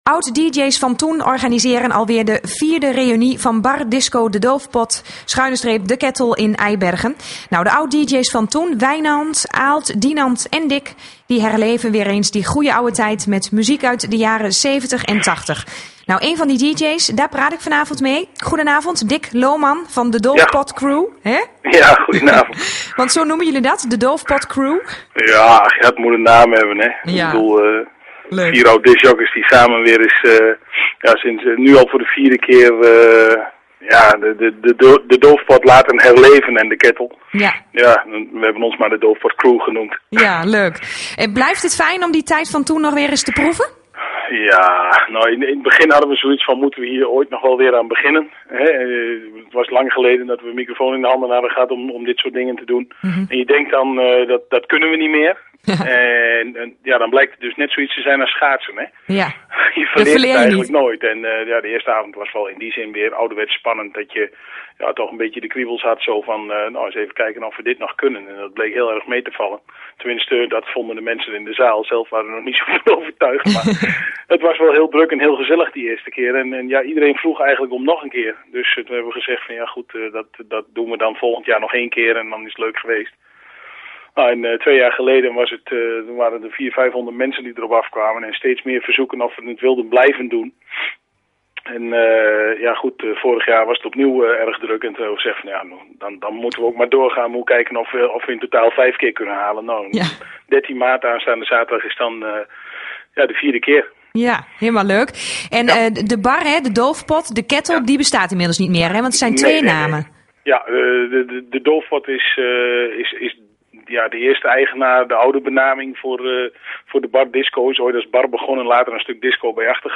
Een van de DJ's zal maandag een intervieuw geven bij Gelre FM.